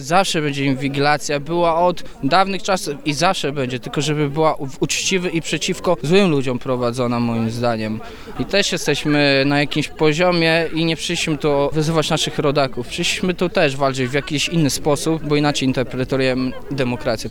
Akcja "Stargard dla demokracji" na Rynku Staromiejskim